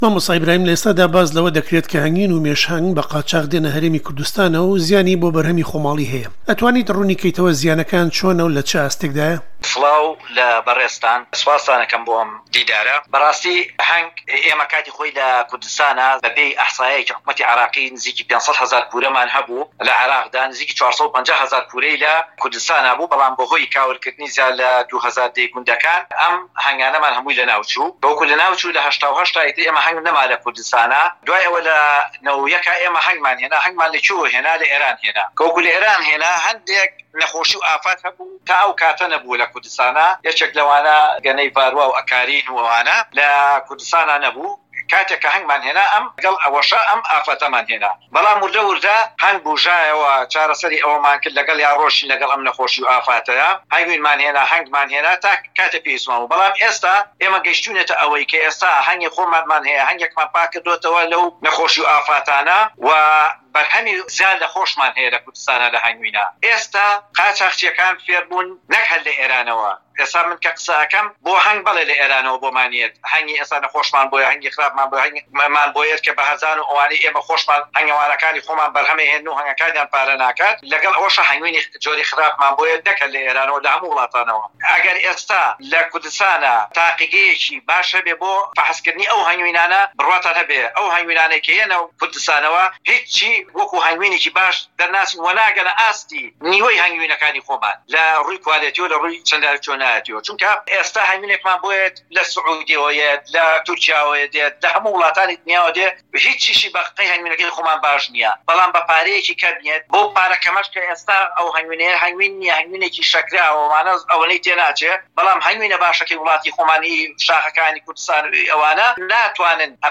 گفتووگۆ